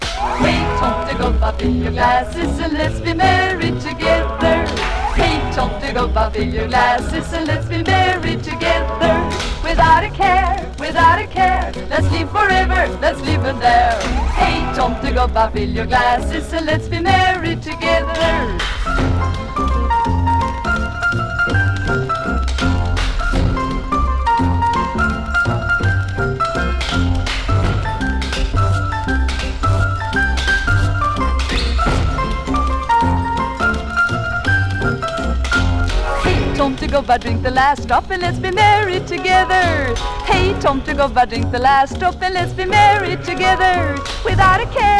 なんと、25回の多重録音で創出した凄み溢れる世界。
Jazz, Pop, Experimental　USA　12inchレコード　33rpm　Stereo